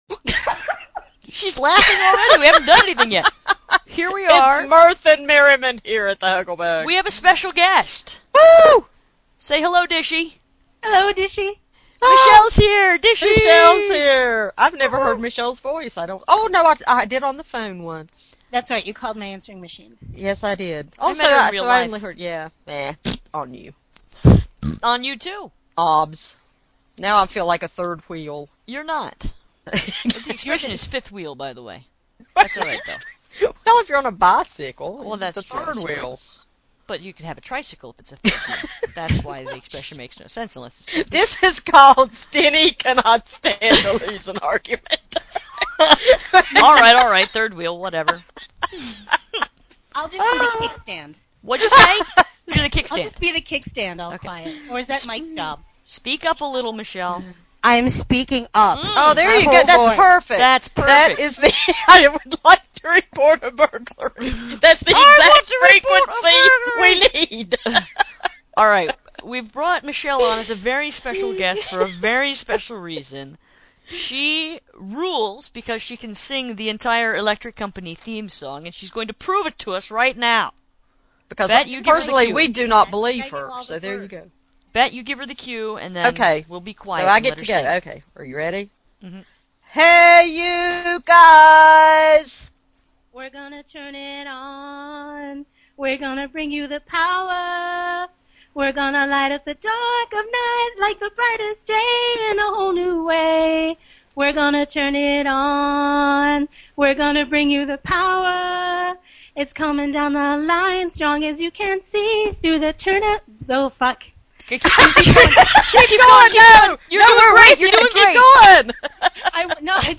We also have a go at the Hogan’s Heroe’s theme, and much merriment ensues.